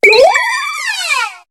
Cri de Mélancolux dans Pokémon HOME.